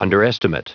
Prononciation du mot underestimate en anglais (fichier audio)
underestimate.wav